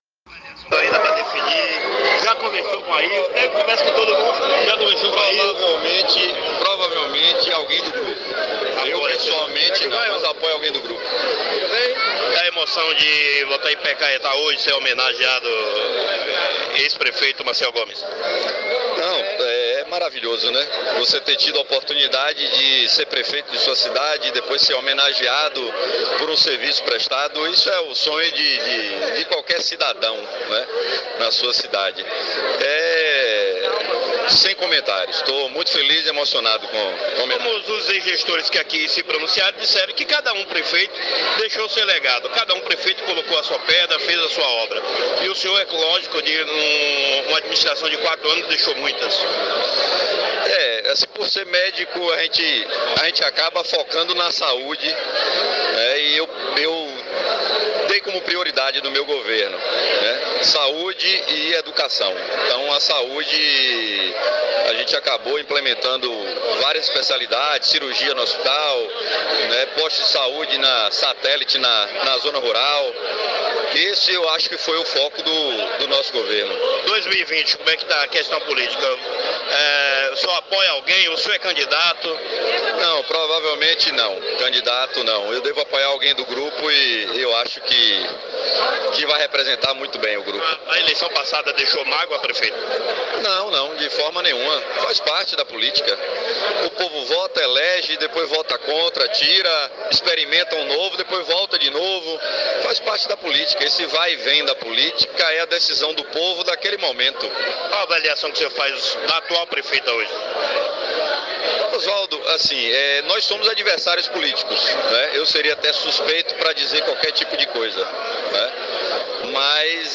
O ex-prefeito de Ipecaetá, Dr. Marcel Gomes, que governou o município de 2013 a 2016, em entrevista ao site Rota da Informação, disse que não será candidato a prefeito na próxima eleição.
Entrevista-de-Marcel-Gomes-online-audio-converter.com_.mp3